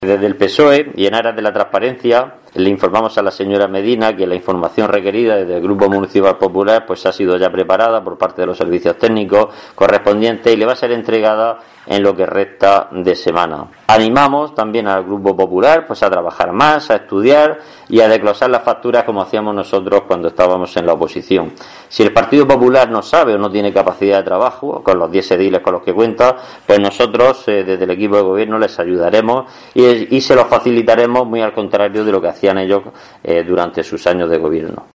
Isidro Abellán, edil de Hacienda sobre facturas COVID